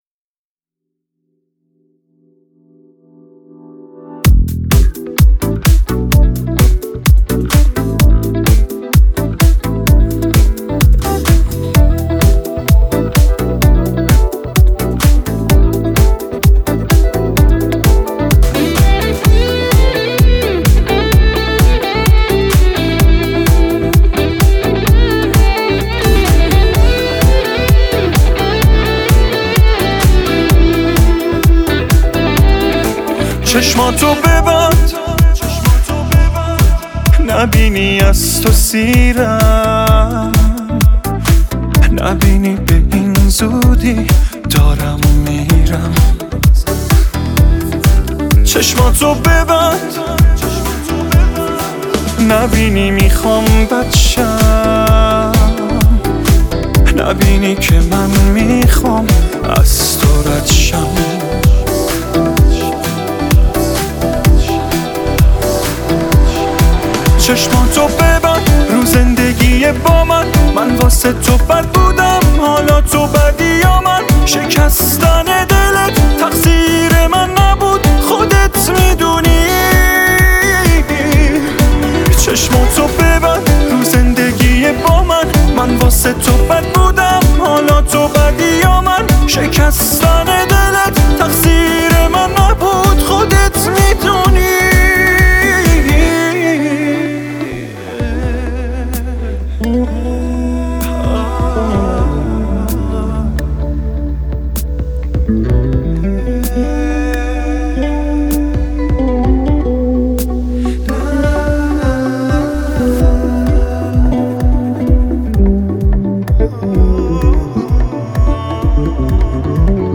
با ریتم 2/4